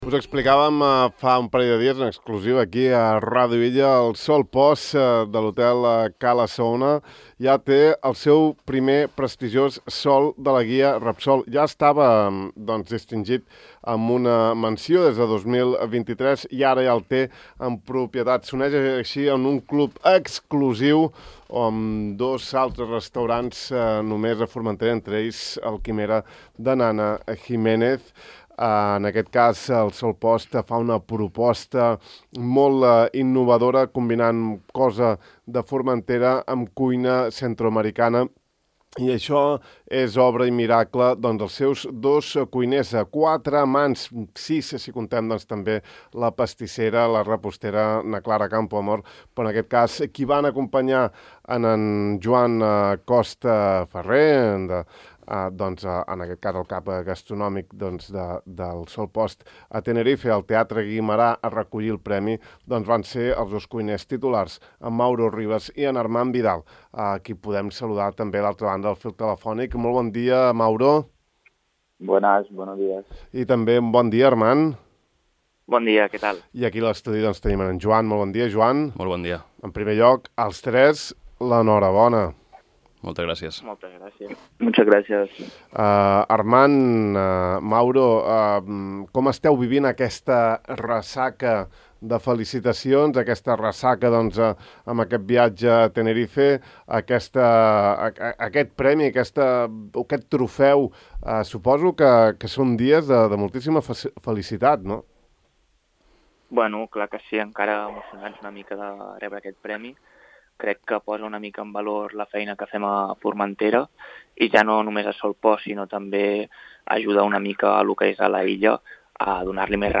De far a far una llarga i distesa conversa